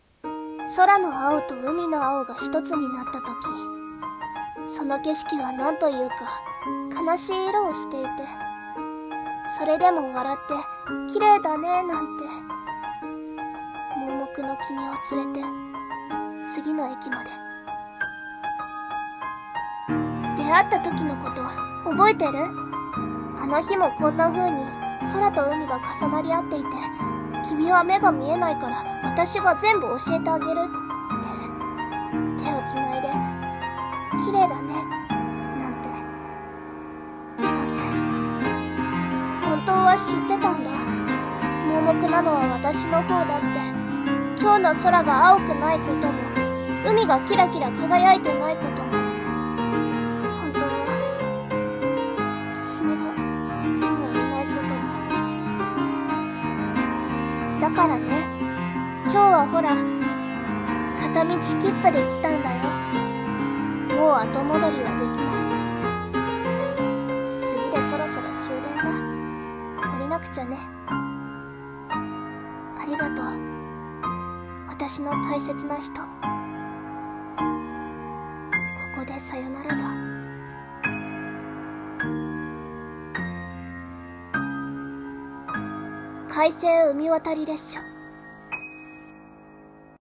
【1人声劇】快晴海渡り列車